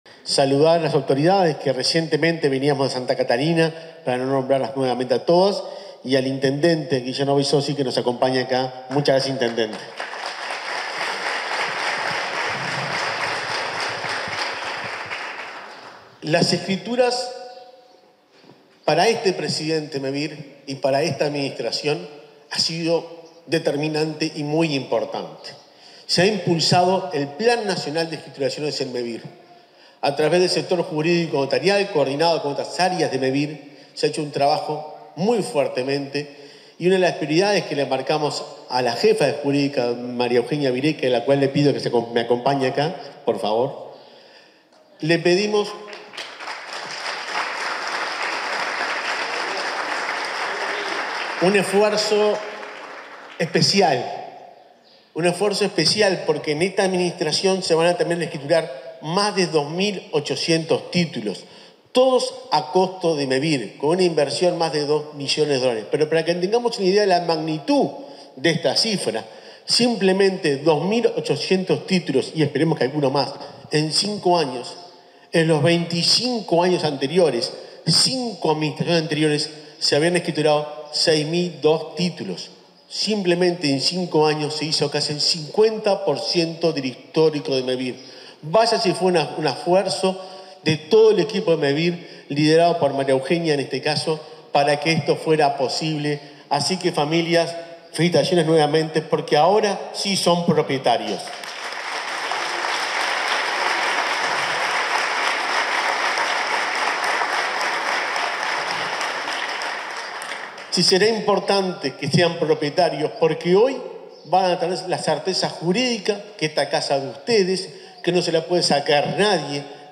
Palabras del subsecretario de Vivienda y el presidente de Mevir
Palabras del subsecretario de Vivienda y el presidente de Mevir 03/12/2024 Compartir Facebook X Copiar enlace WhatsApp LinkedIn En el marco de una ceremonia de entrega de escrituras en José Enrique Rodó, Soriano, se expresaron el subsecretario de Vivienda y Ordenamiento Territorial, Tabaré Hackenbruch, y el presidente de Mevir, Juan Pablo Delgado.